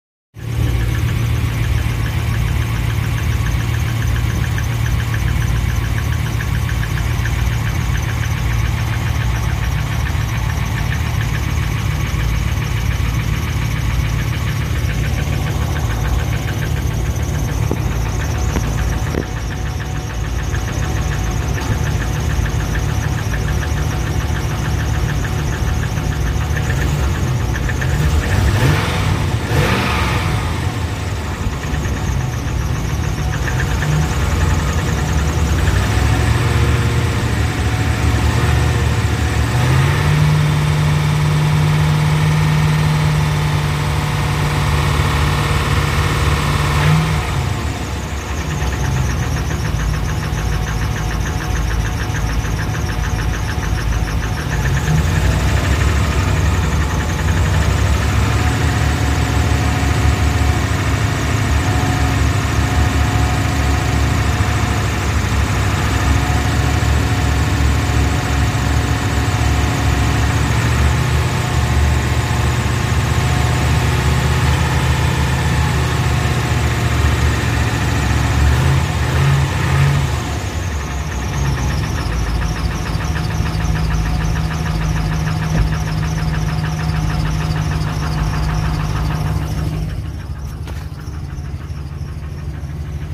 truck.m4a